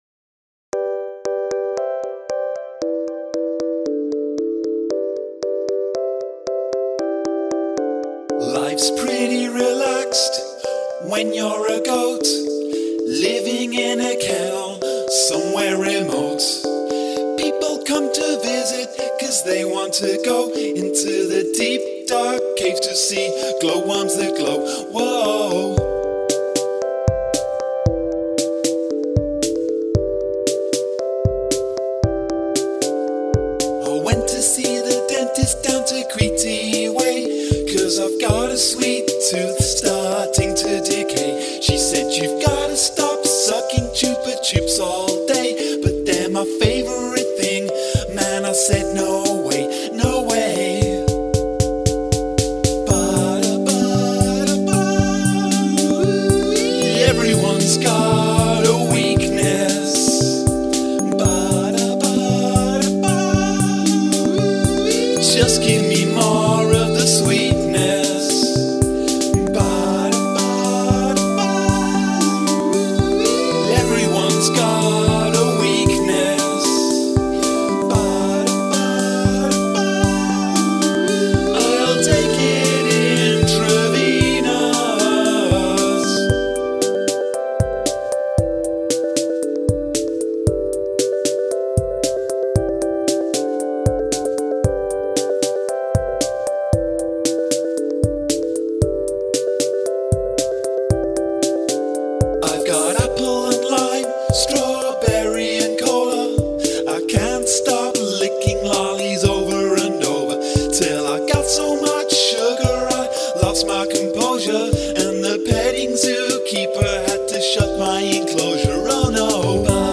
Oooh, cool panning.
I love that little “oh no” though.